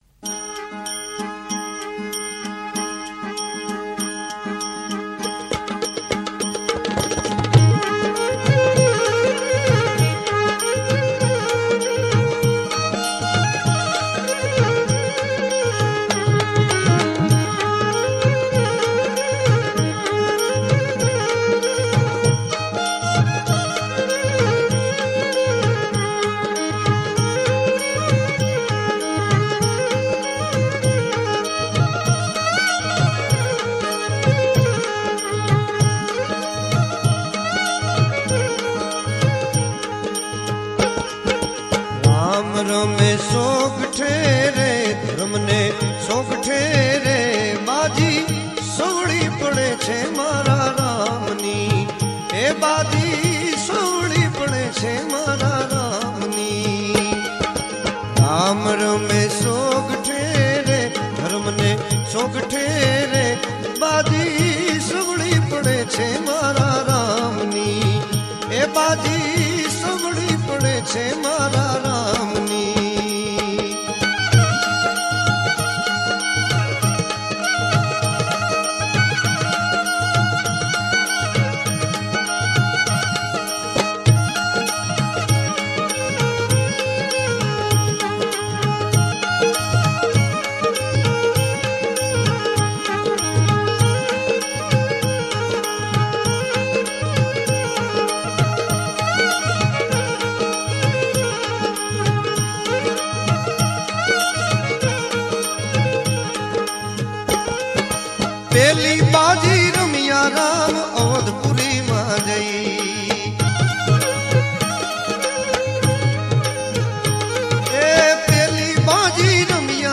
Gujarati Bhajan